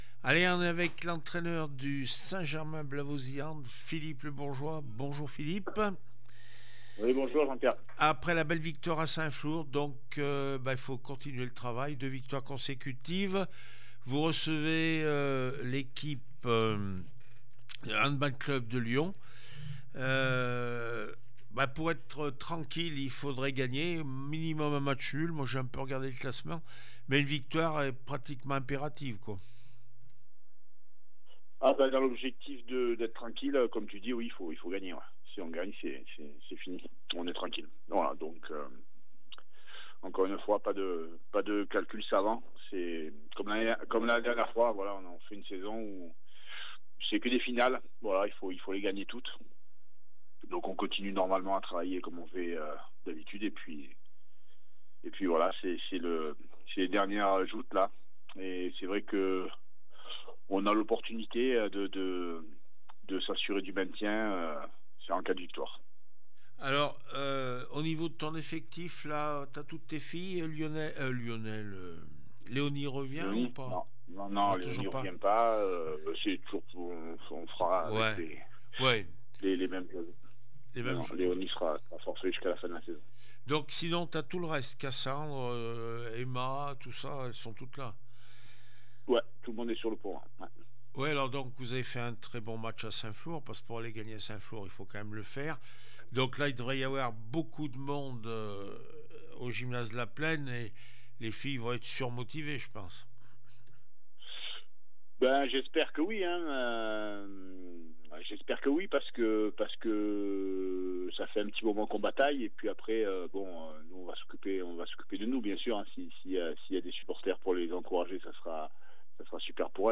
9 mai 2025   1 - Sport, 1 - Vos interviews